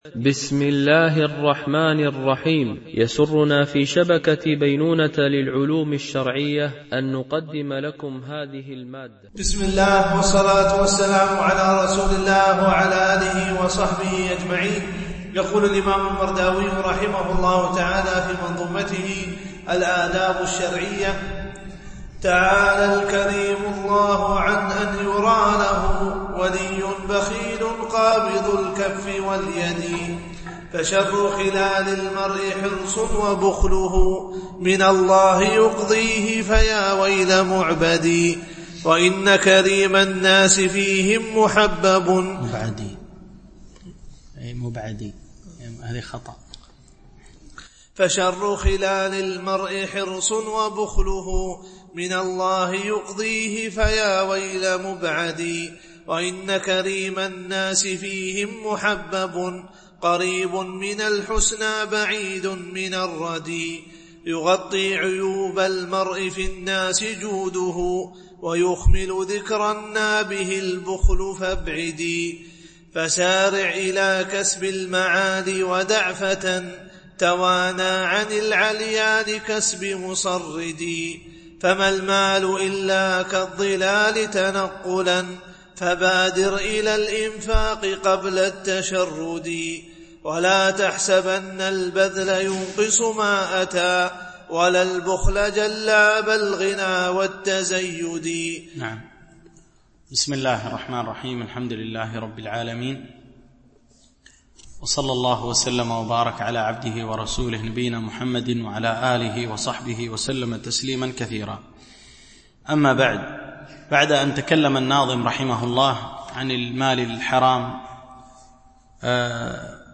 شرح منظومة الآداب الشرعية – الدرس47 ( الأبيات 748-773 )